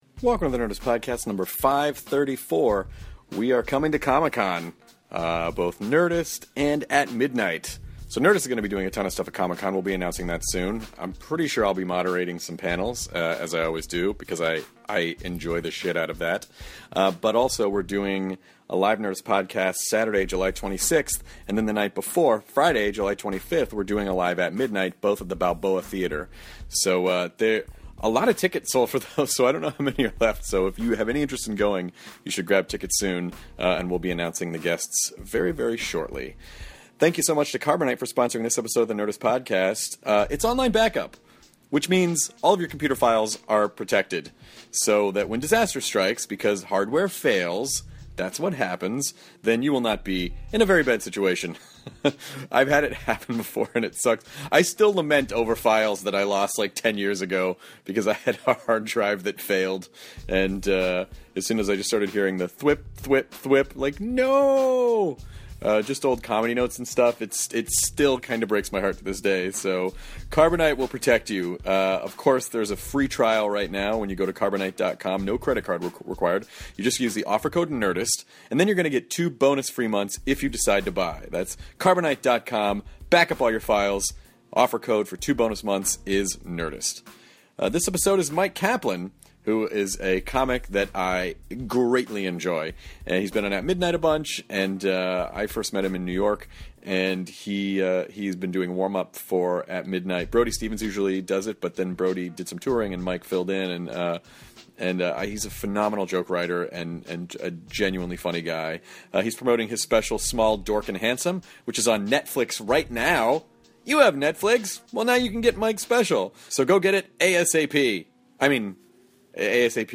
Comedian Myq Kaplan chats with Chris and Jonah about the dangers of creating the same bit as another comedian and looking back at the jokes they wrote as new comedians; they make fun of Jonah’s past relationships and talk about his new special Small, Dork and Handsome, on Netflix now!